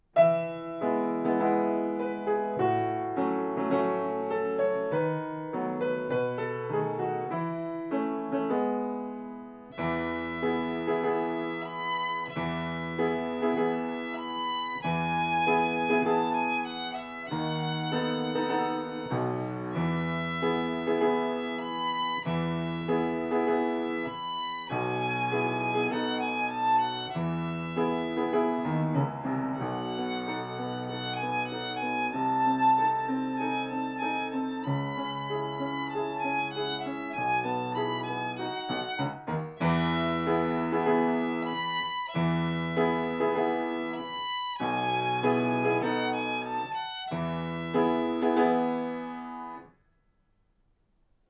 ご自宅での練習用に録音しました。